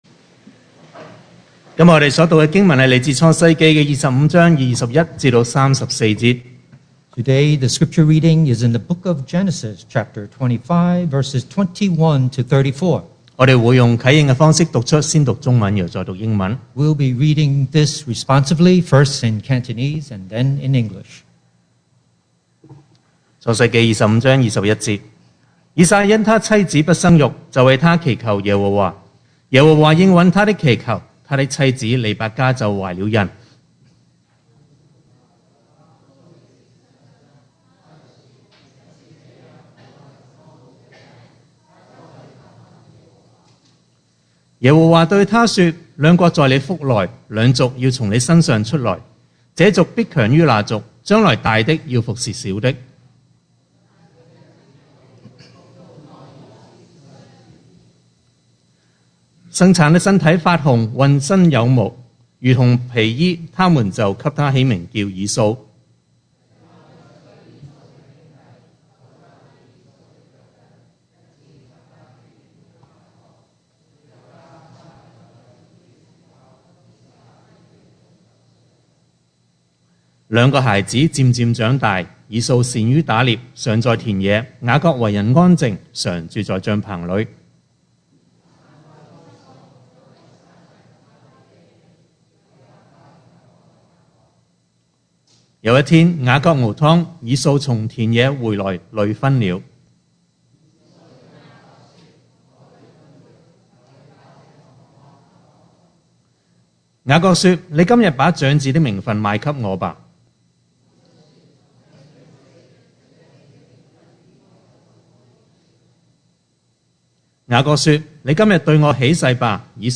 2022 sermon audios
Service Type: Sunday Morning